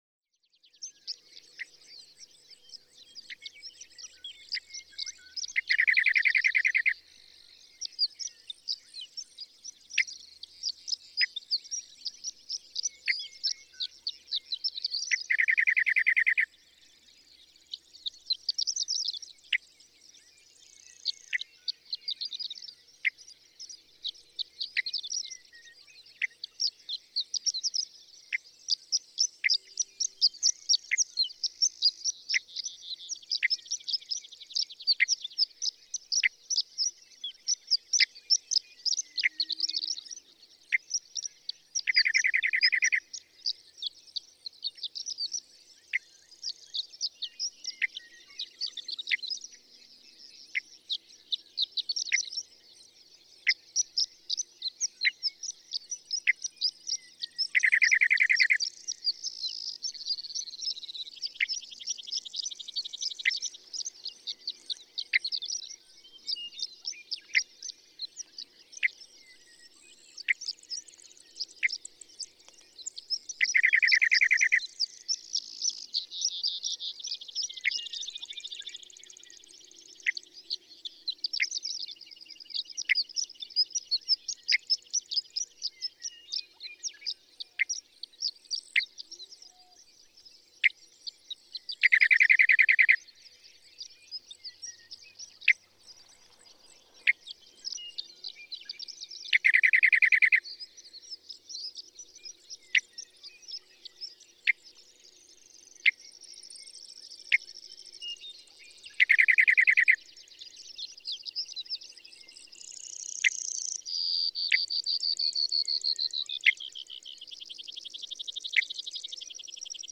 ♫619. Western meadowlark: Innate calls are a chup, chup, followed by a dry, rolling chatter, vicicicicicicicic. Singing horned lark in background.
Murphy's Pasture, Pawnee National Grassland, Colorado.
619_Western_Meadowlark.mp3